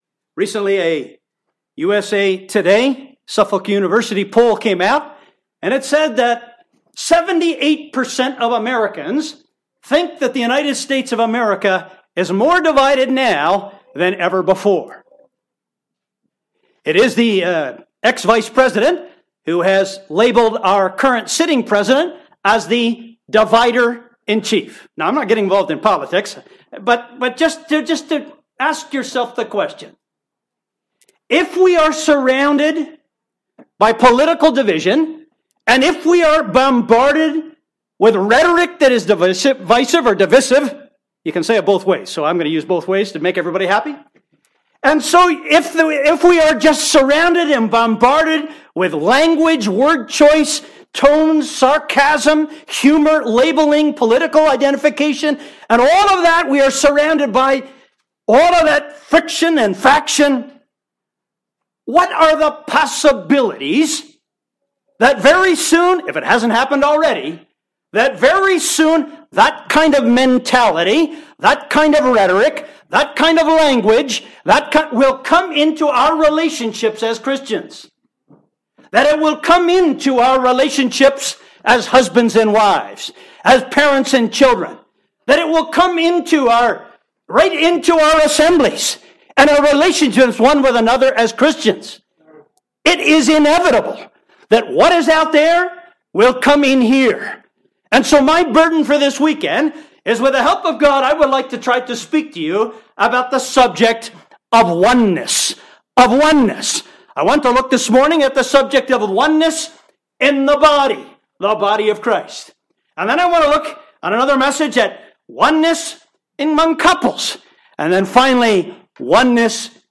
Readings: Gal 3:28, Eph 4:1-6, 1 Cor 12:12-27. (Recorded at the Hickory Gospel Hall conference, NC, USA, 2019)